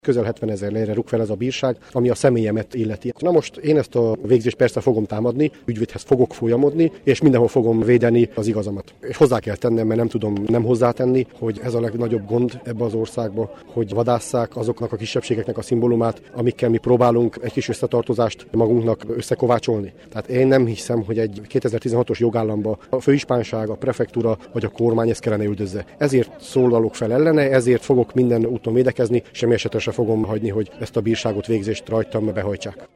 A rendezvény szervezője Bíró József Attila, Maros megyei tanácsos: